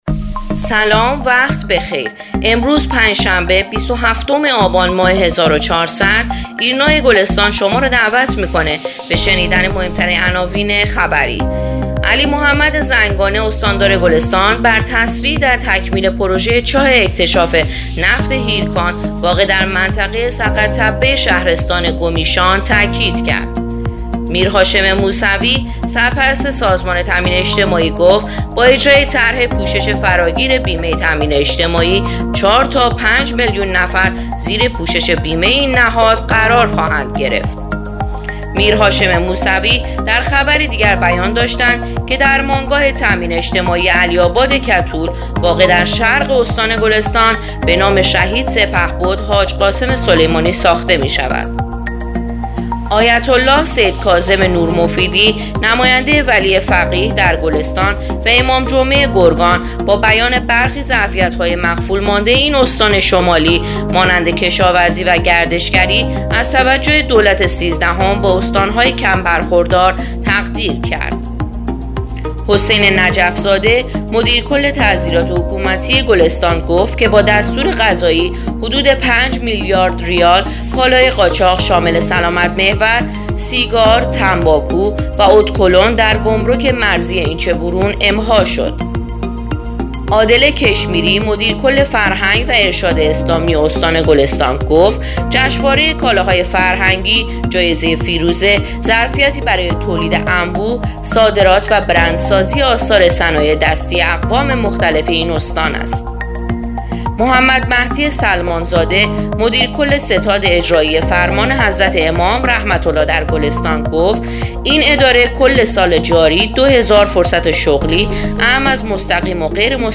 پادکست: اخبار شامگاهی بیست و هفتم آبان ایرنا گلستان